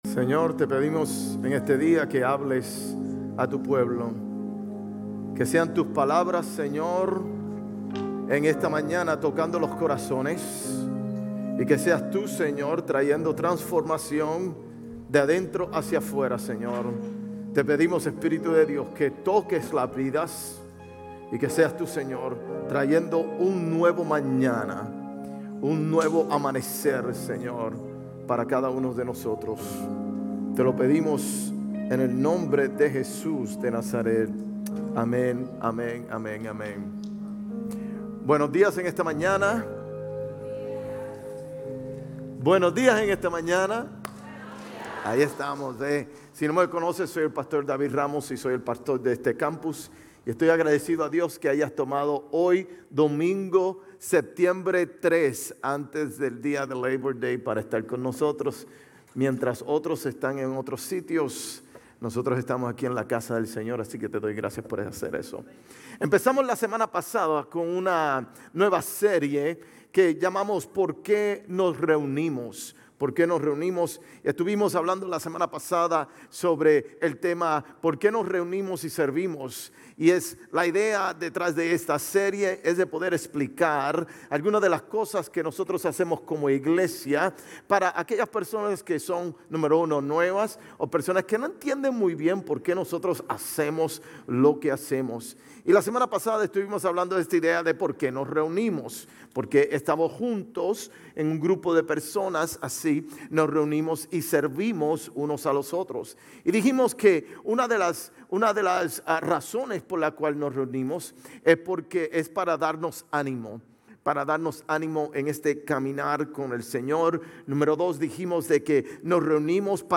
GCC-GE-September-3-Sermon.mp3